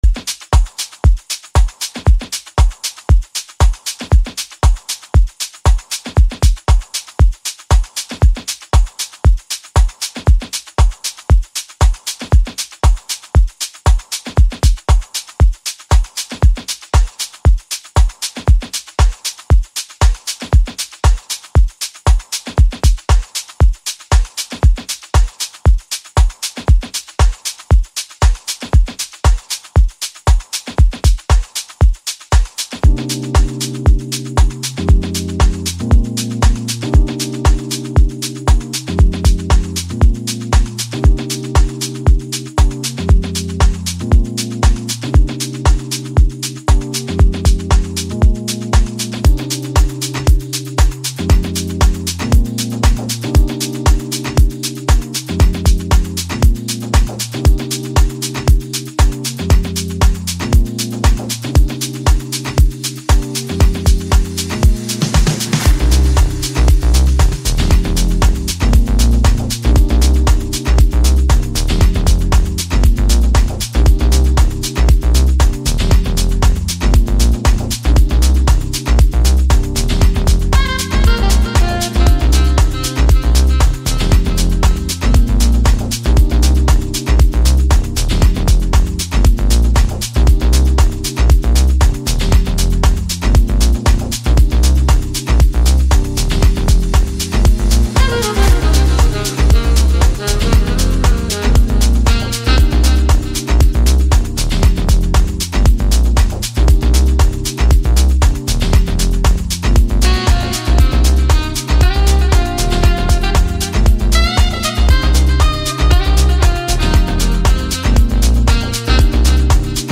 Home » Amapiano » DJ Mix » Hip Hop
South African singer-songsmith